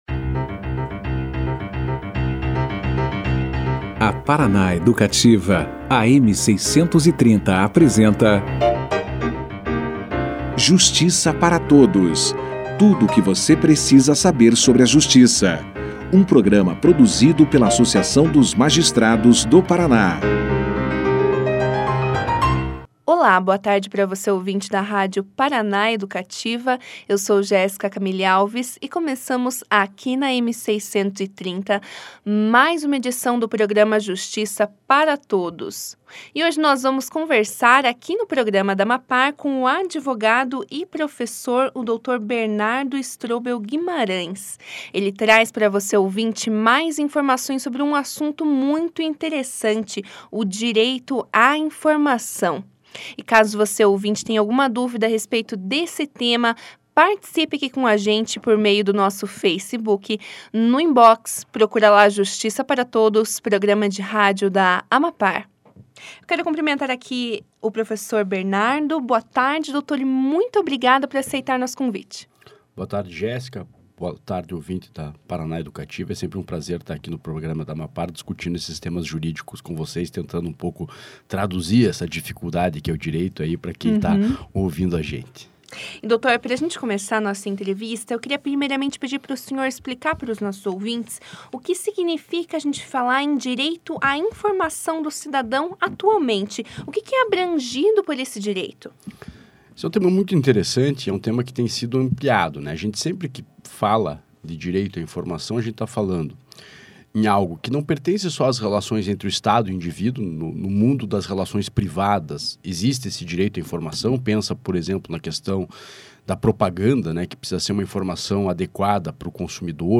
O convidado explicou no que consiste o direito a informação e quais informações a população pode consultar por meio dos portais da transparência. Na oportunidade, o professor também apontou as consequências jurídicas para os municípios e gestores públicos, caso não cumpram os dispositivos das leis de acesso a informação e da transparência. Confira aqui a entrevista